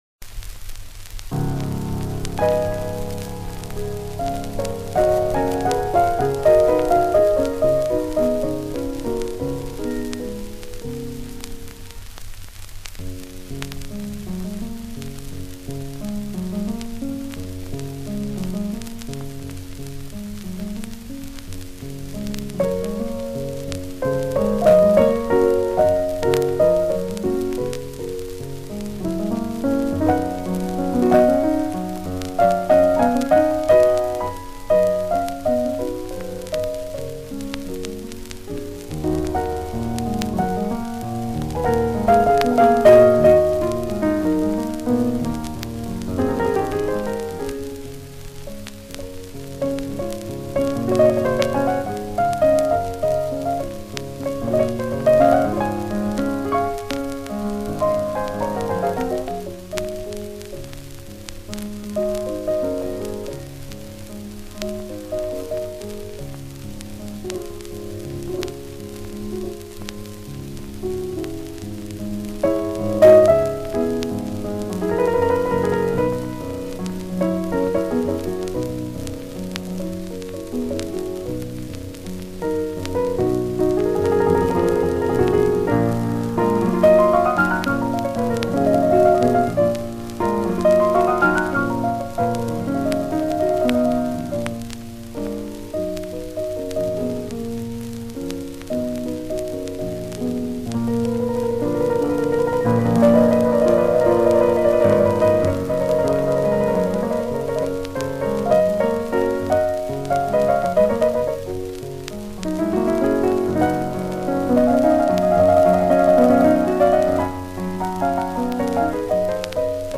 Музыка была эстрадная, ритмичная, не фортепиано